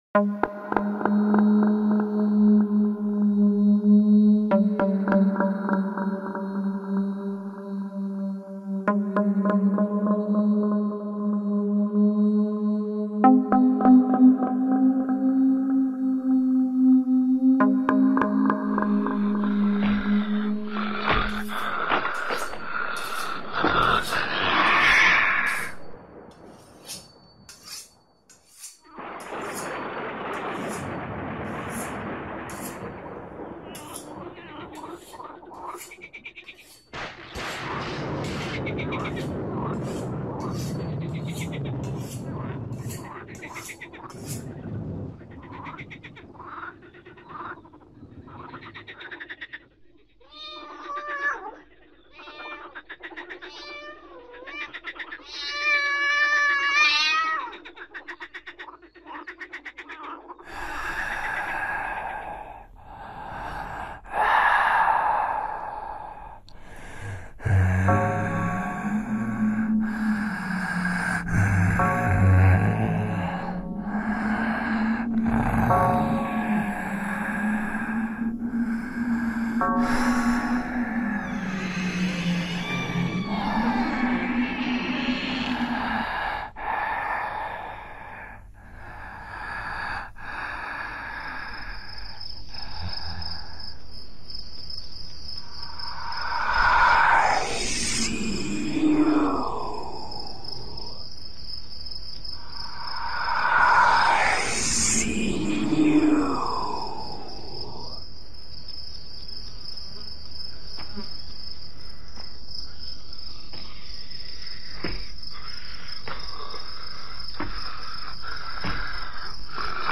KGooTENadnC_SONIDOS-DE-TERROR-8D.mp3